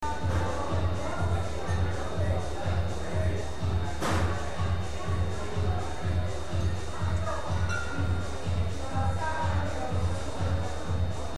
With some techno sounds.